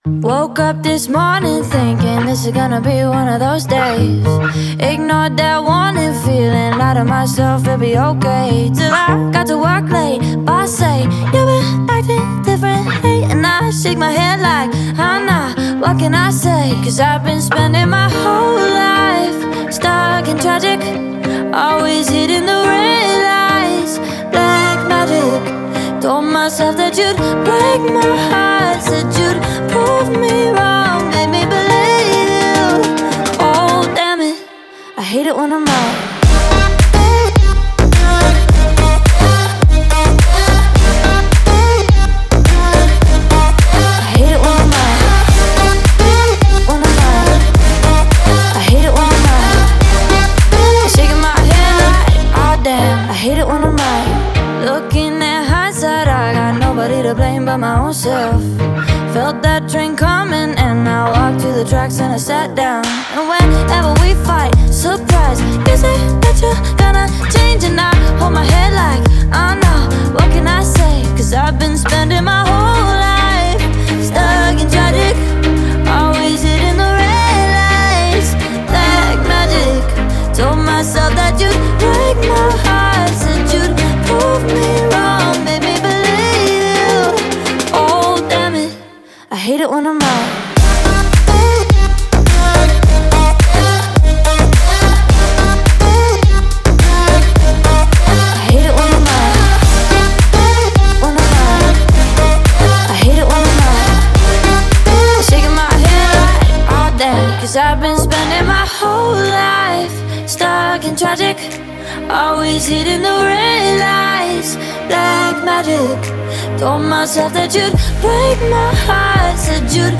энергичная электронная танцевальная композиция